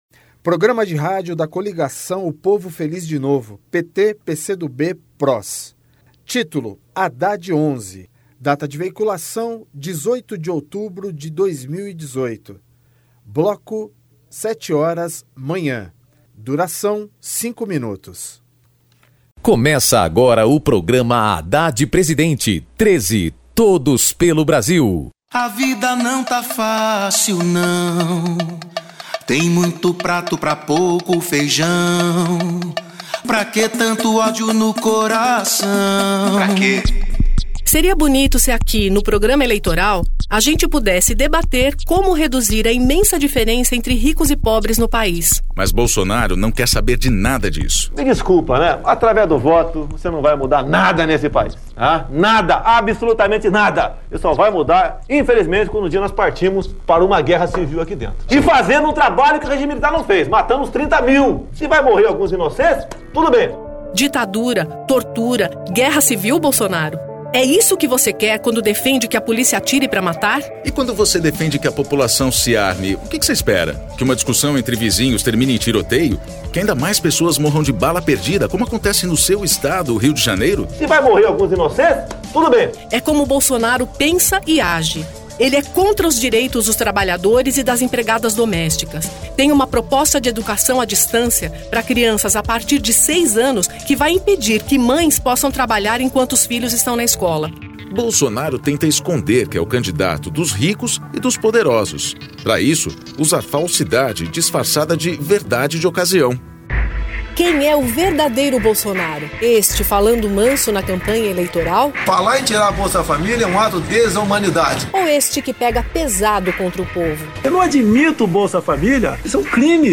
TítuloPrograma de rádio da campanha de 2018 (edição 41)